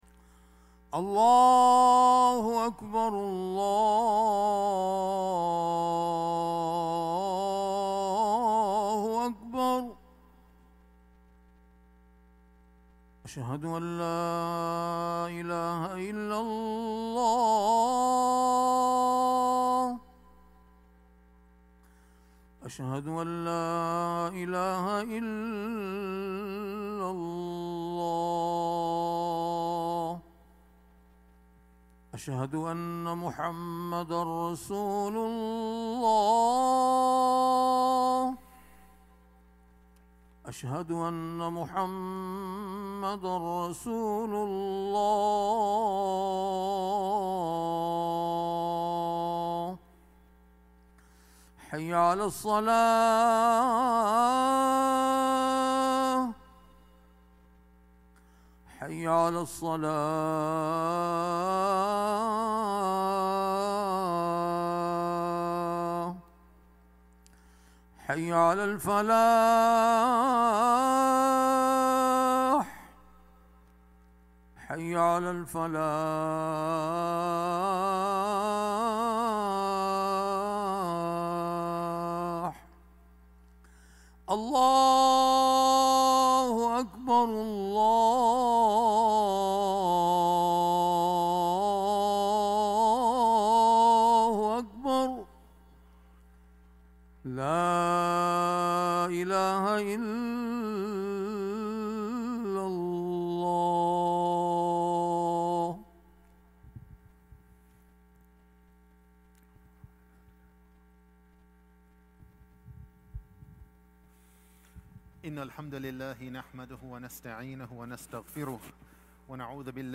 Friday Khutbah - "Why do we act that way?"